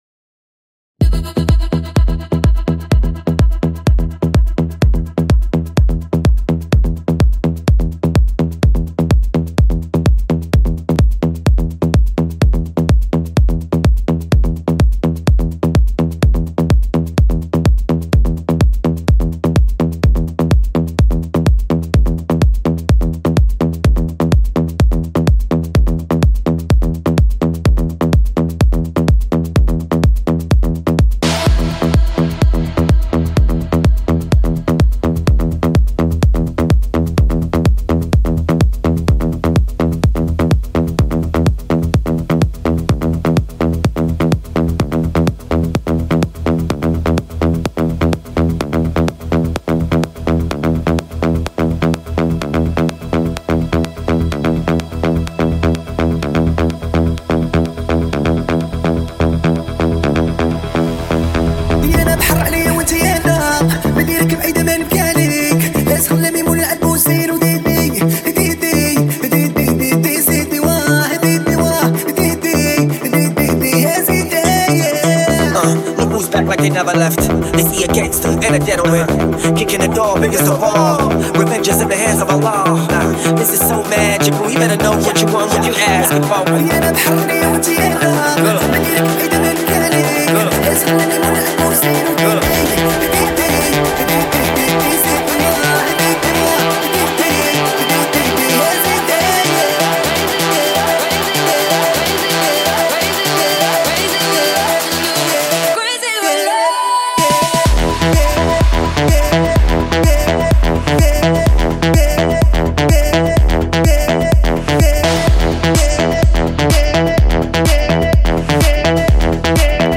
• 🎧 Energetic EDM drops with smooth build-ups
• 🔊 Deep bass and high-quality sound mastering